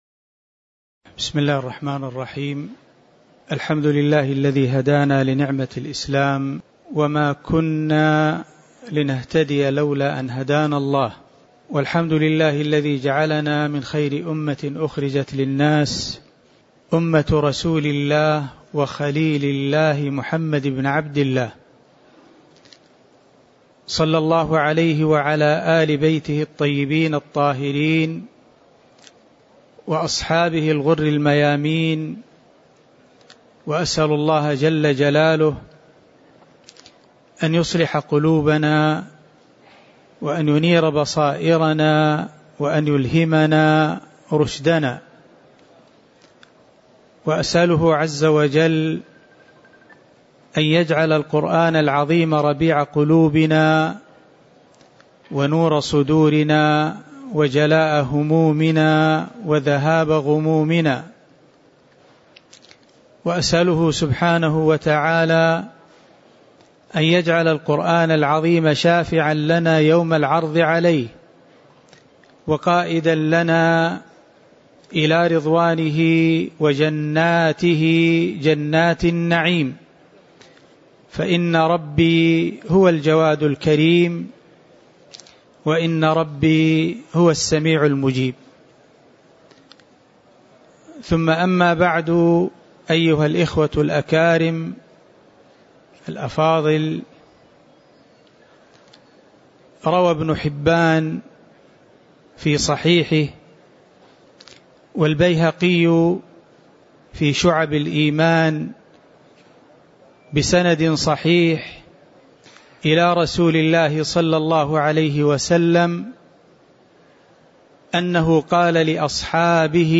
تاريخ النشر ١٧ صفر ١٤٤٣ هـ التفسير المكان: المسجد النبوي الشيخ